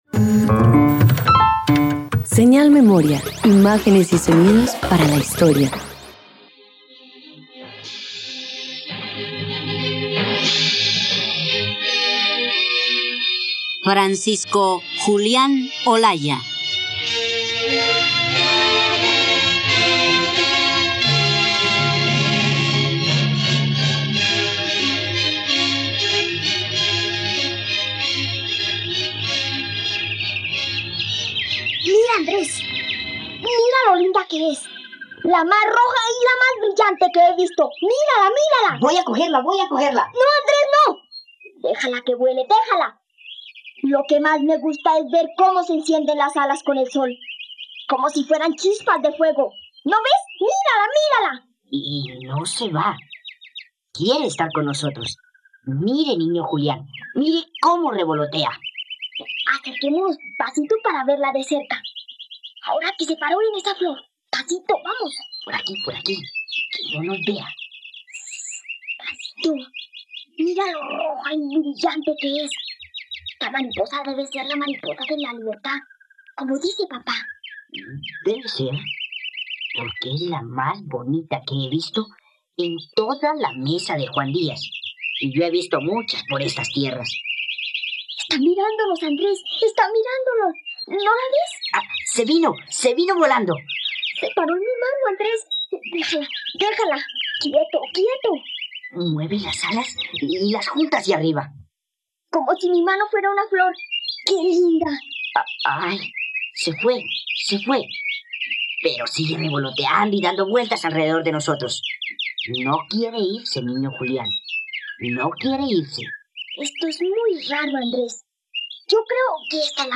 Francisco Julian Olaya - Radioteatro dominical | RTVCPlay
..Esta es la dramatización sobre la vida del mártir Francisco Julián Olaya, hijo de un militar de la independencia.